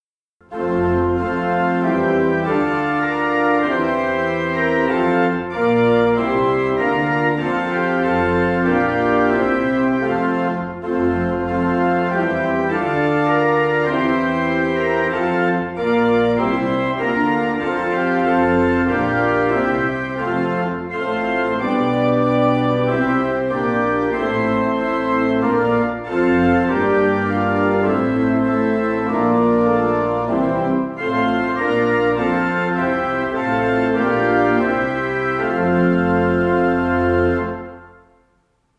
Orgel
Unsere Orgel (Orgel-Mayer, Heusweiler) wurde im Sommer 2012 durch die Orgelbaufirma Förster & Nicolaus (Lich) gründlich gereinigt und repariert und hat einen vollen und harmonischen Klang.